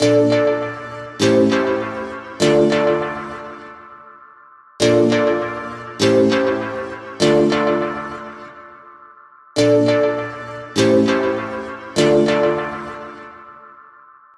Heavy Thoughts. Instrumental Music.